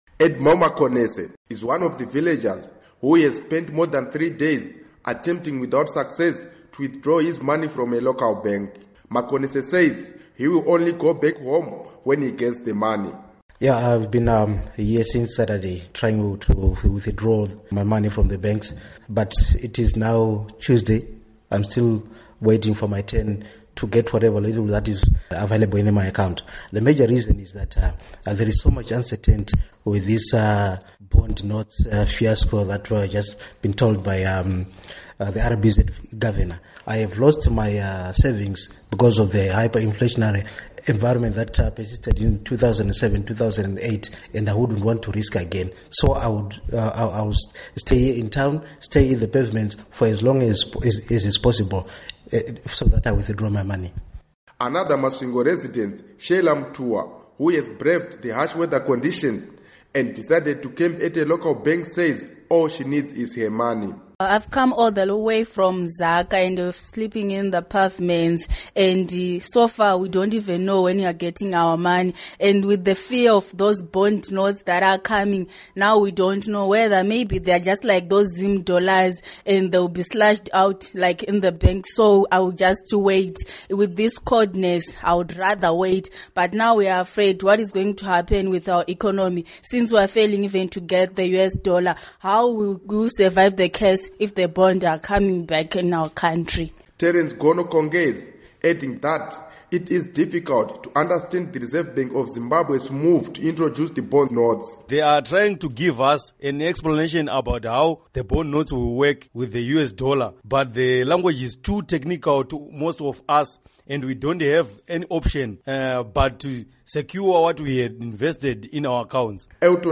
Report on Bond Notes